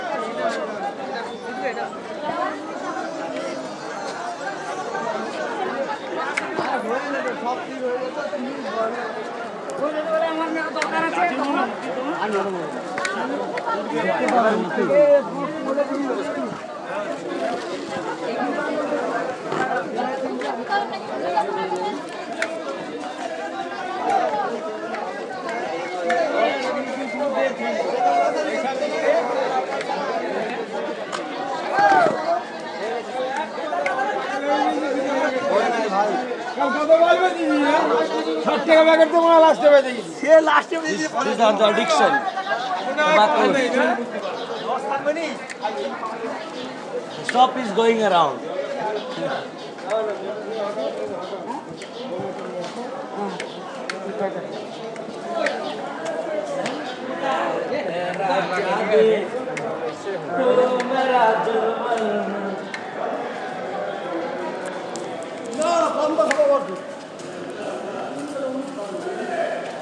Dans le marché aux fleurs de Kolkata